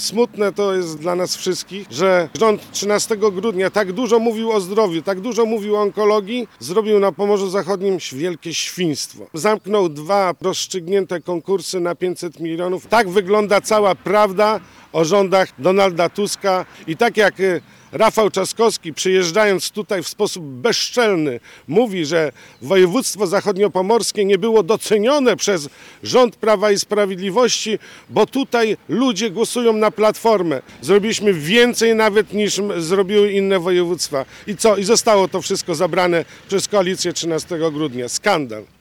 Podczas dzisiejszej konferencji prasowej poseł Prawa i Sprawiedliwości Artur Szałabawka obarczył winą za tę sytuację obecną ekipę rządzącą.